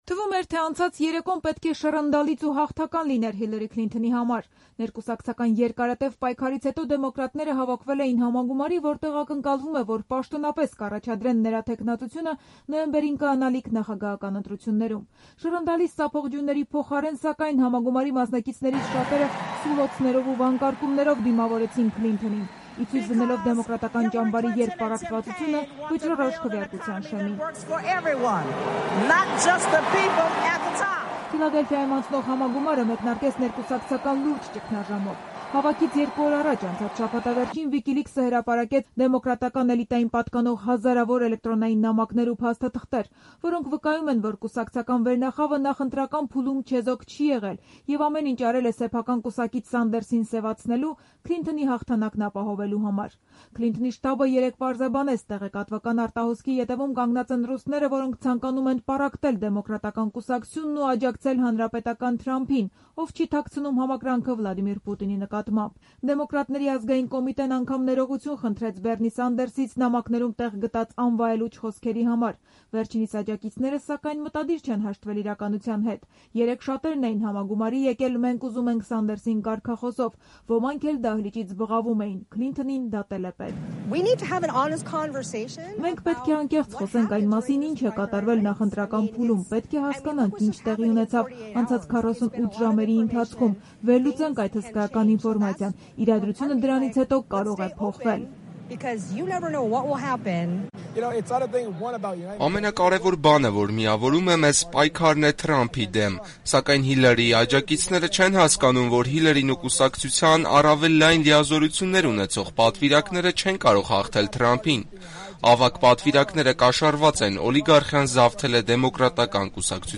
Քլինթոնին ծափողջյունների փոխարեն սուլոցներով դիմավորեցին